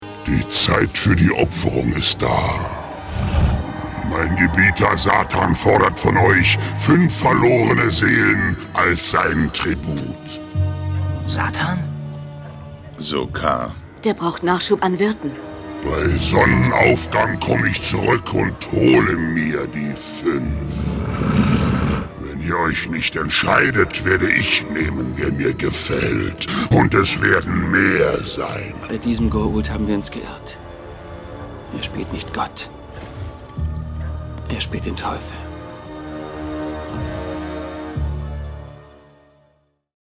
Der Goa'uld (SG1 3x08) spricht.